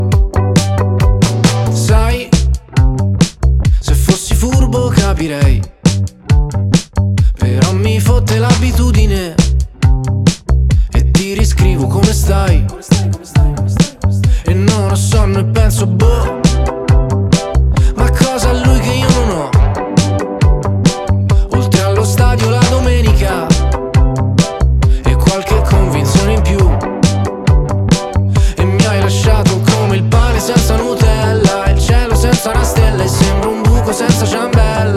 2025-05-02 Жанр: Поп музыка Длительность